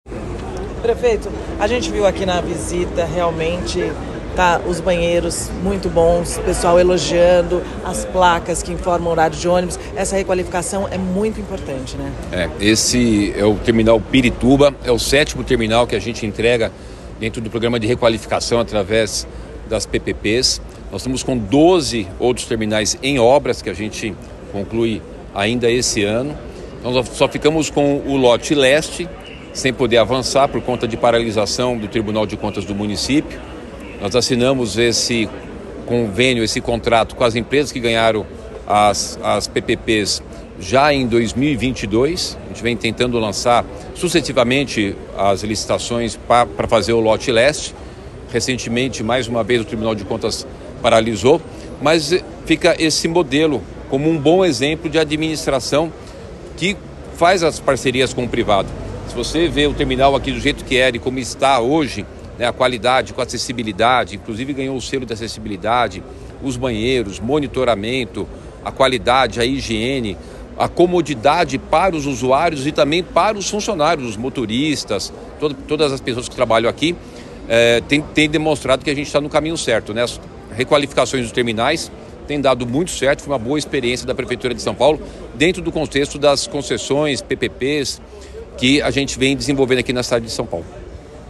OUÇA: Prefeito Ricardo Nunes entrega revitalização do Terminal Pirituba nesta segunda-feira (10)
Nesta segunda-feira, 10 de junho de 2024, o Prefeito Ricardo Nunes realizou a entrega do Terminal Pirituba, na Zona Oeste de São Paulo, após obras de requalificação.